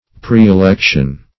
Preelection \Pre`["e]*lec"tion\, n.